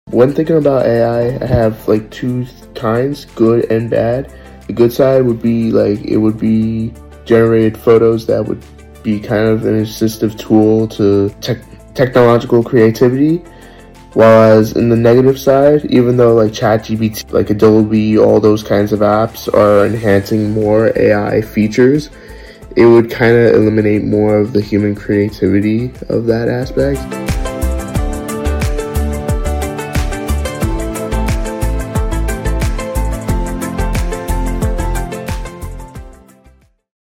[Video Description: The video starts with 'NomadVerse' logo at the top left with young man with a light-to-medium complexion, short dark hair, and a neatly trimmed beard.